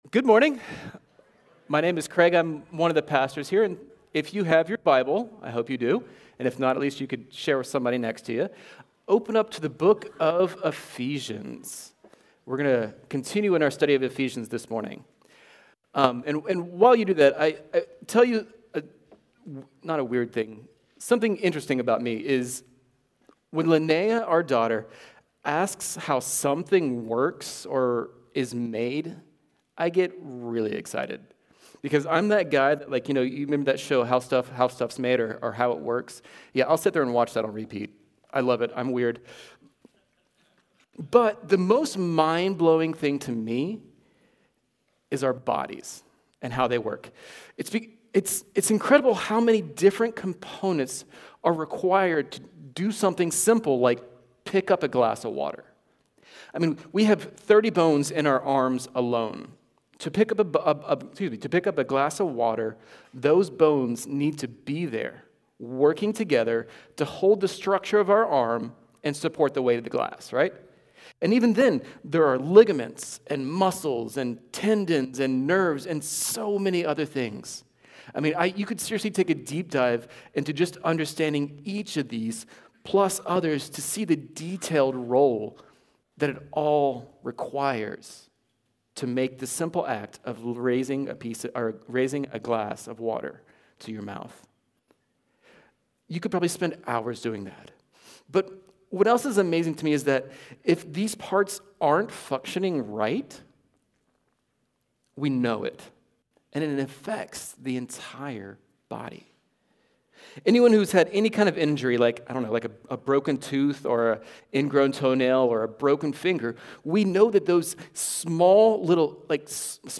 Sunday-Service-5-26-24.mp3